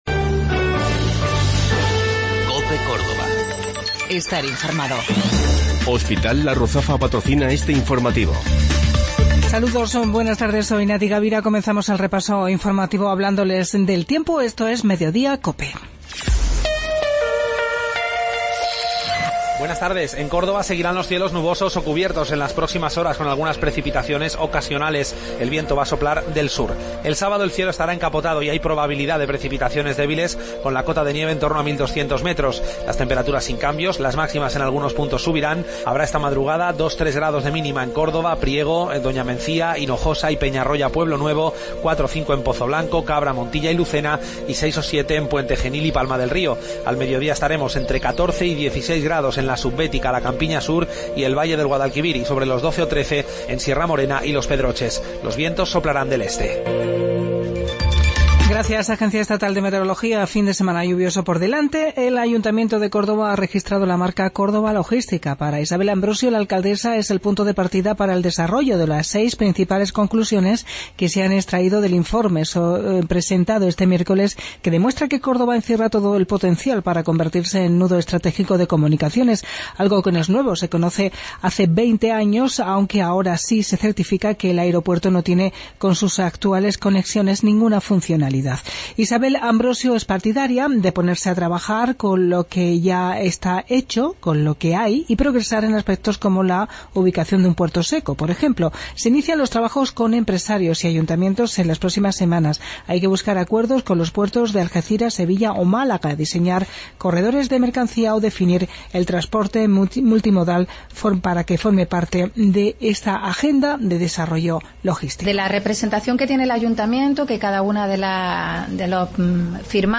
Mediodía en Cope. Informativo local 10 de Febrero 2017